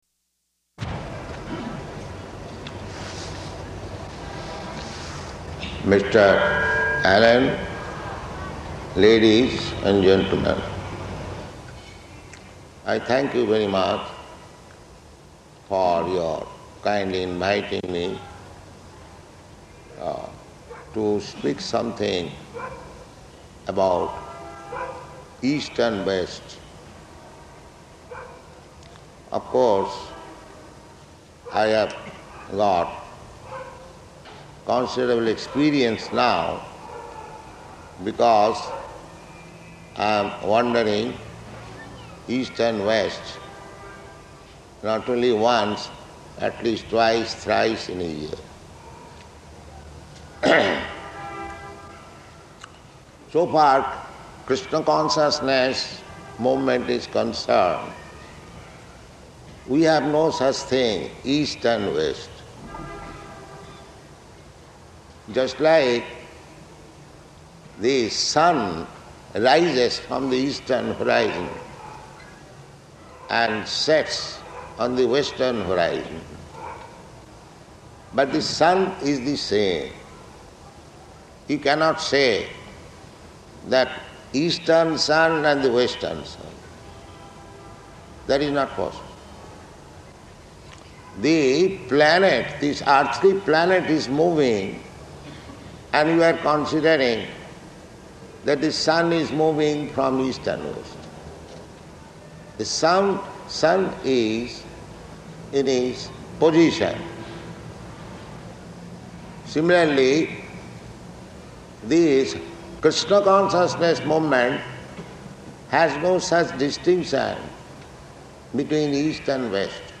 Lecture at Indo-American Society
Type: Lectures and Addresses
Location: Calcutta